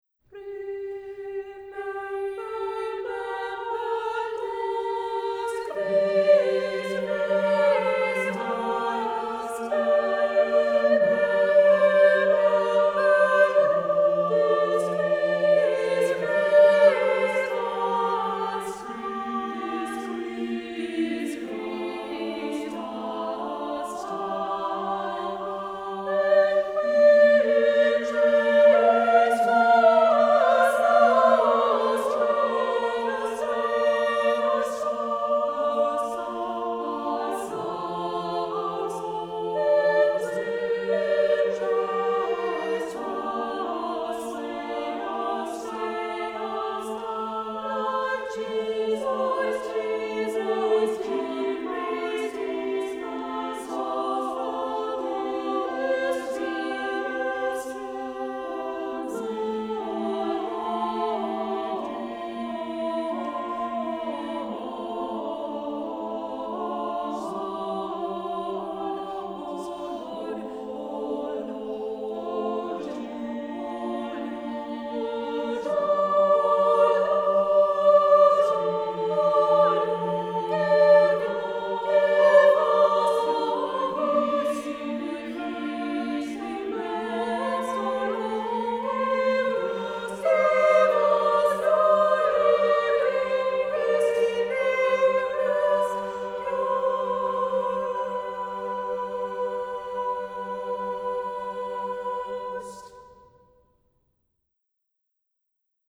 Voicing: SSA; SAB